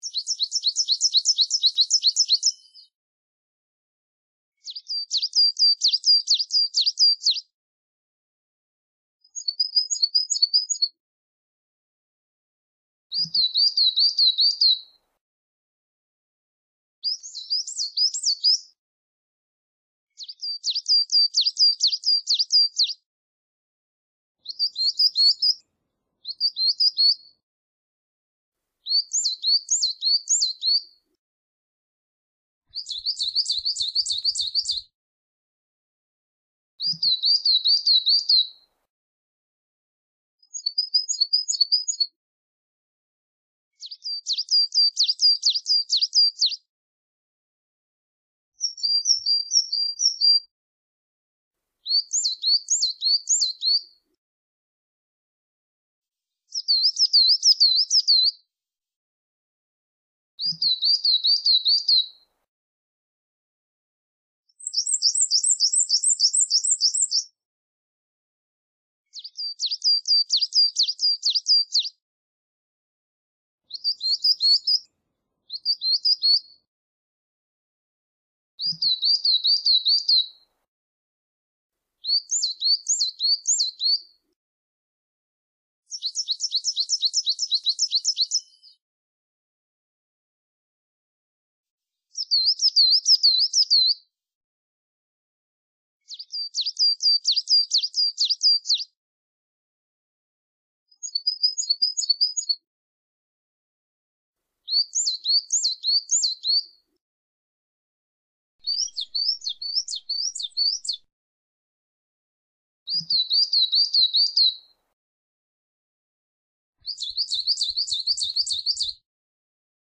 Bastankara-Otusu.mp3